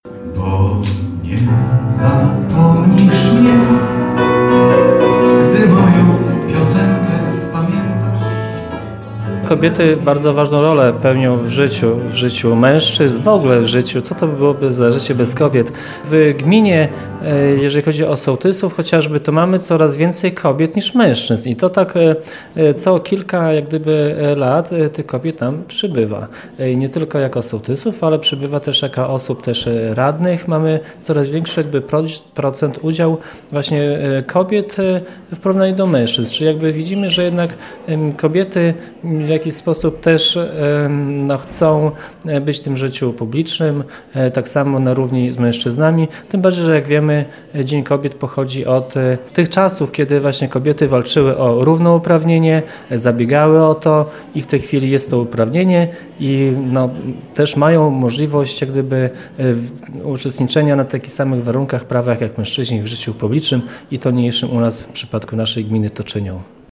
Dzień Kobiet co prawda przypada w niedzielę (8 marca), ale w Gminie Suwałki świętowano już w piątek (06.03).
Życzenia Paniom składał Zbigniew Mackiewicz, wójt gminy.